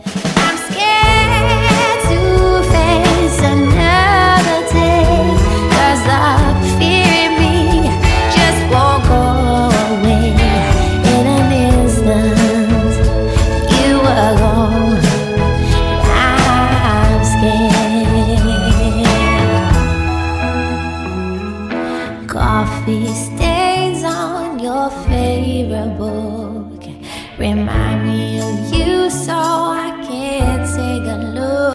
pop singer